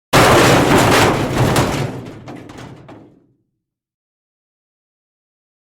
Download "Metal Crash" — a free SFX sound effect.
Metal Crash
yt_P_B8BLaqGZY_metal_crash.mp3